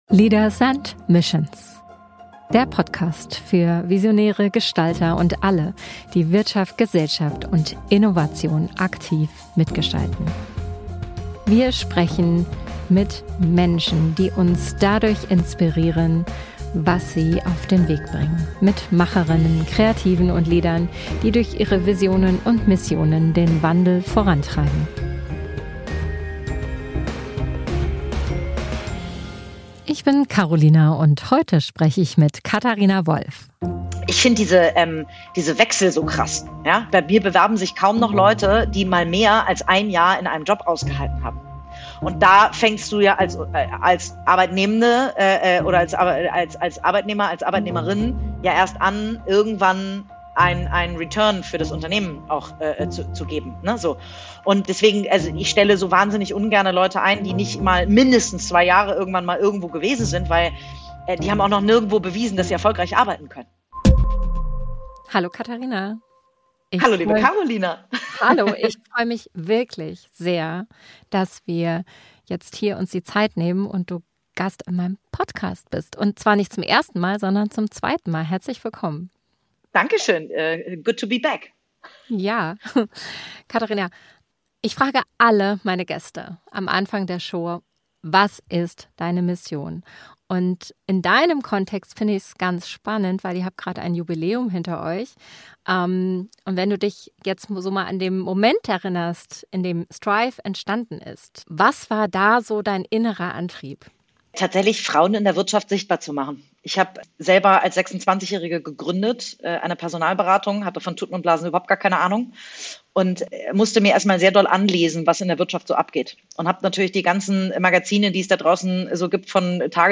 Ein Gespräch über Sichtbarkeit, Unternehmertum und die Kraft von Medien, Realität zu verändern.